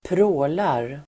Ladda ner uttalet
Folkets service: pråla pråla verb, show off Grammatikkommentar: A & med x Uttal: [²pr'å:lar] Böjningar: prålade, prålat, pråla, prålar Definition: vara (alltför) grann och lysande Avledningar: prålig (gaudy, showy)